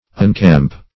Search Result for " uncamp" : The Collaborative International Dictionary of English v.0.48: Uncamp \Un*camp"\, v. t. [1st pref. un- + camp.]